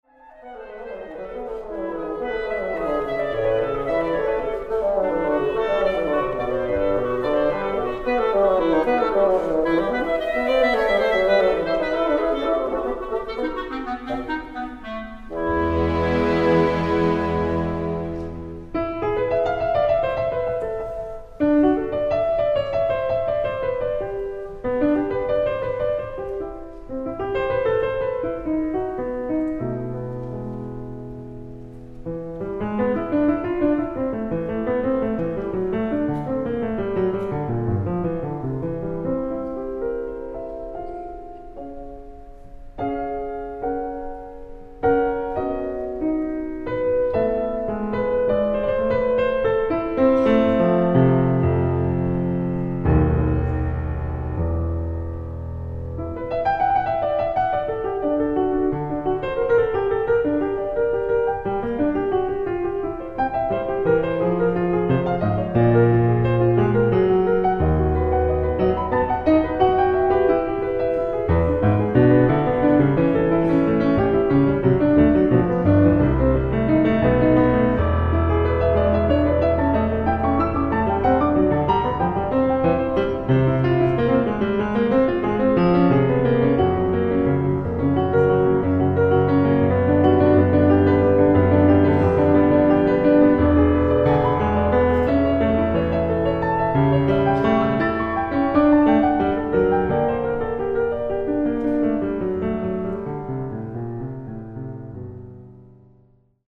ライブ・アット・ルントフンクハウス、ベルリン、ドイツ 06/14/2025
※試聴用に実際より音質を落としています。